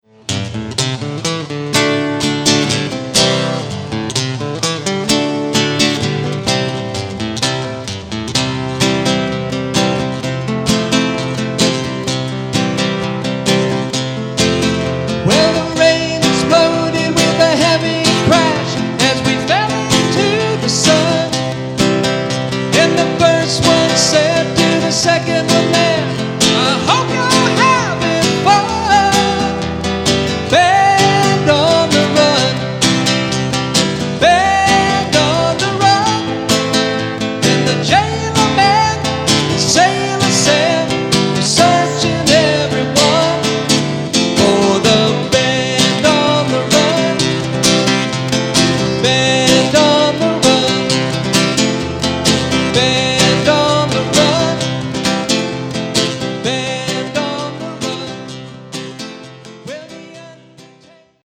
(recorded live)